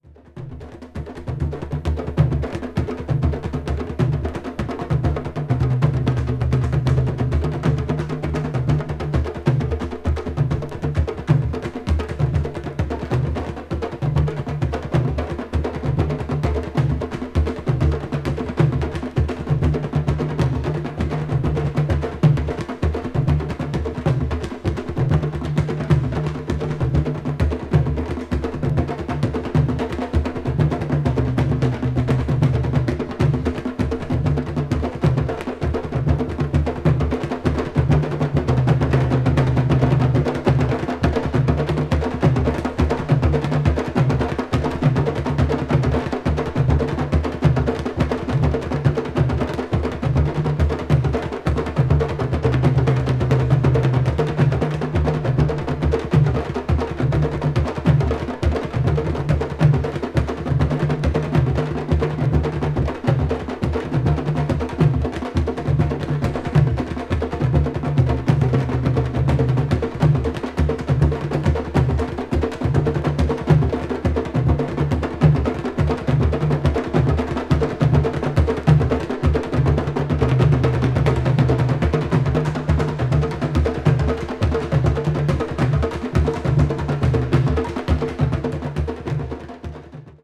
crossover   ethnic jazz   fusion   jazz groove   latin jazz